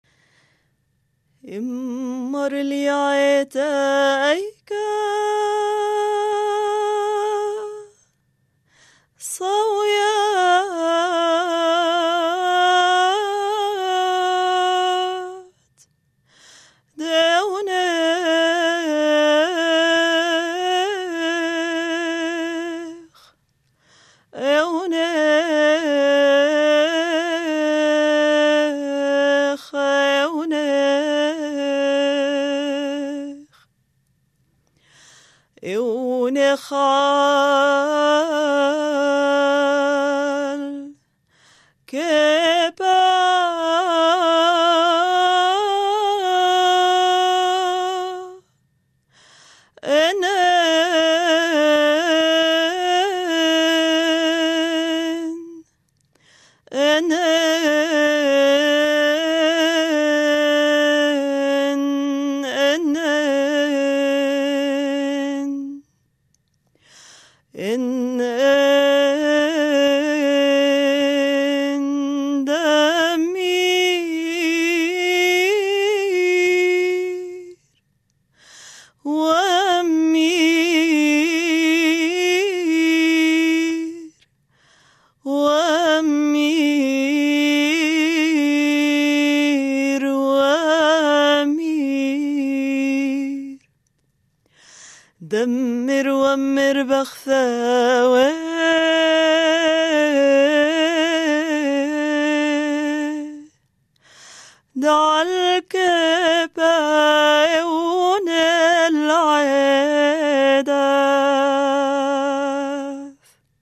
Jego specyfika pod względem muzycznym polega między innymi na tym, że do dziś zachował ćwierćtony, które nie są już znane w świecie zachodnim.
Śpiew z liturgii chaldejskiej o Chrystusie budującym Kościół na skale